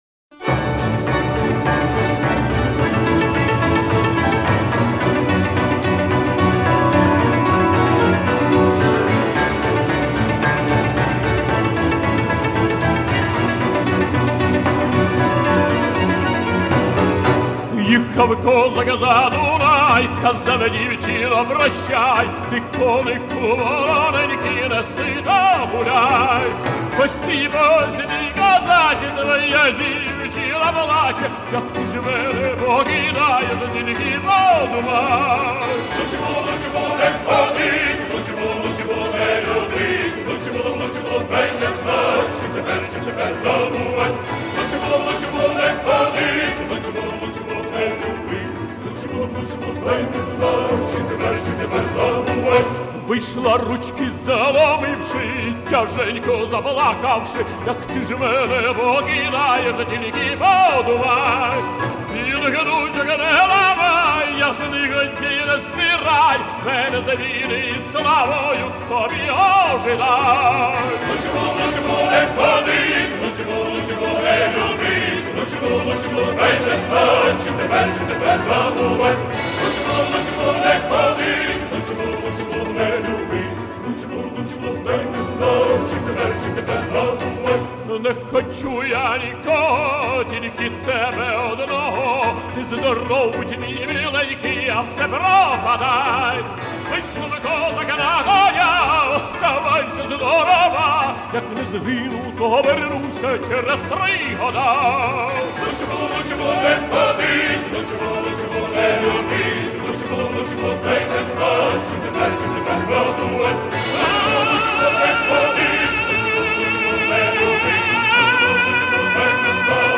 Украинские народные песни